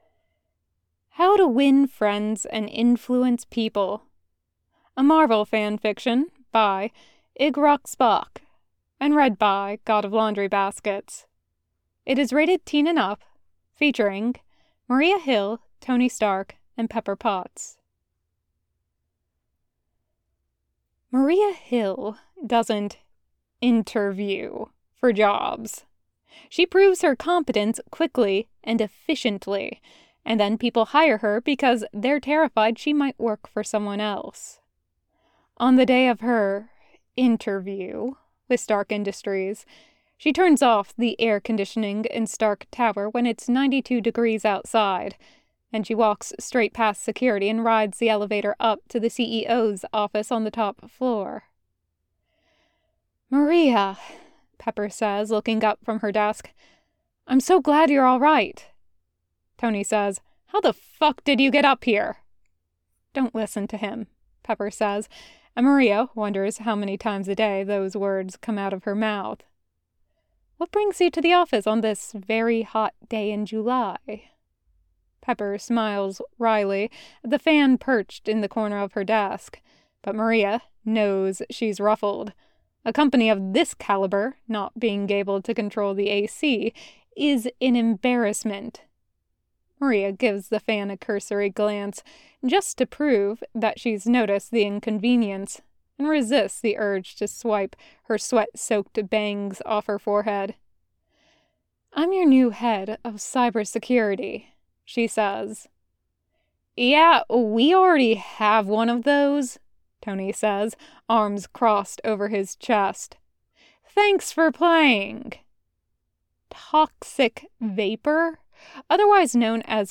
Was done originally for the "No Editing (no makeup)" challenge for voiceteam so there's a few flubs that made it in but, all in all, I'm very happy with it!